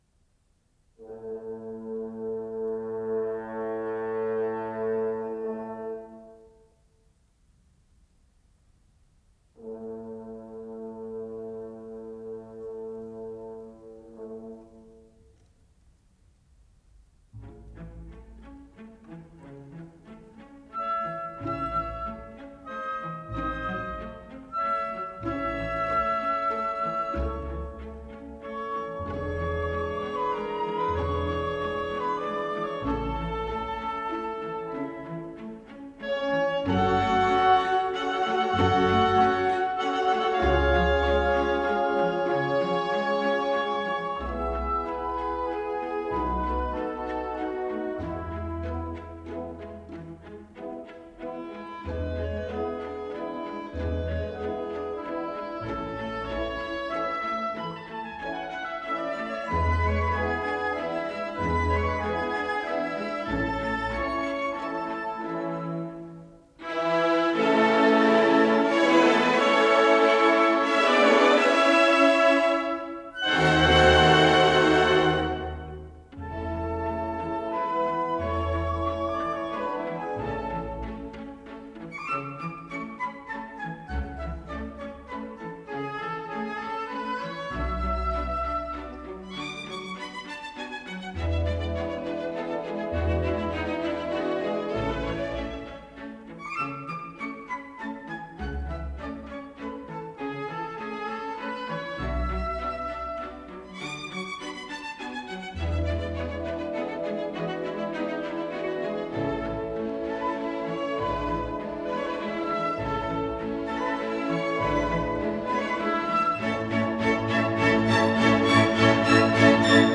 Жанр: Opera